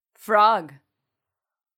frog.mp3